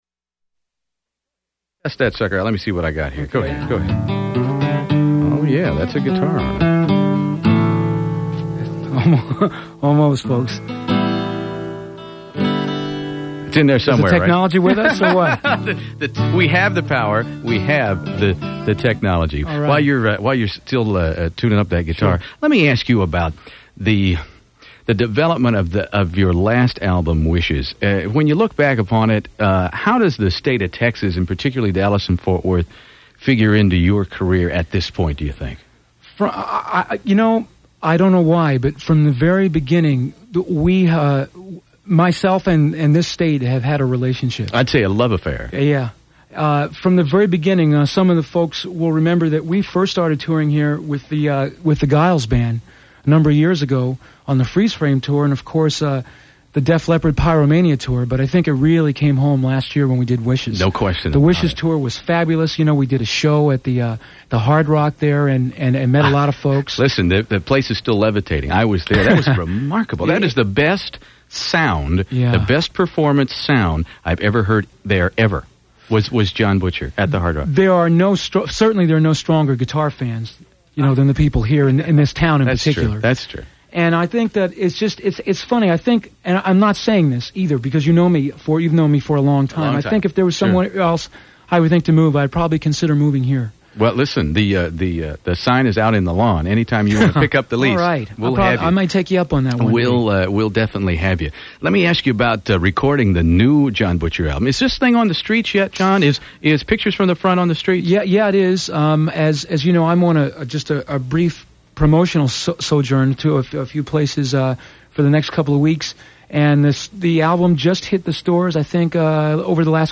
live and solo acoustic